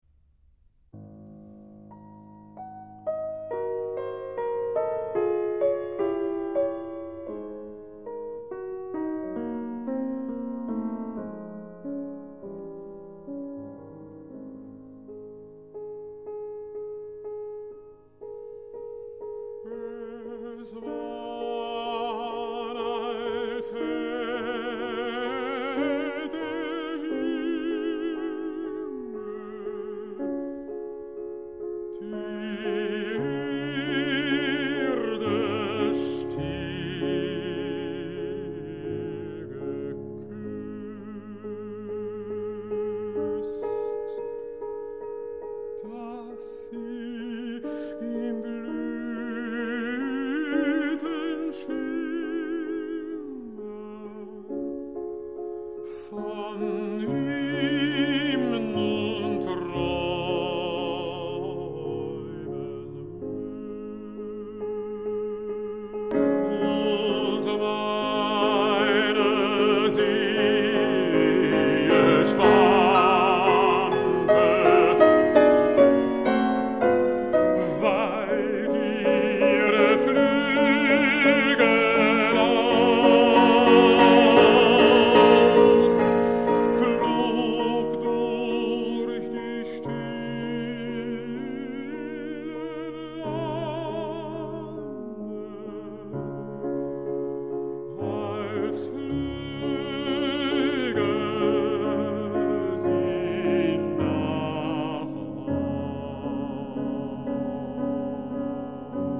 arranged for carillon
Melody is best put in middle layer. Quiet accompaniment in top layer. No sudden changes.
Smoothest transitions possible.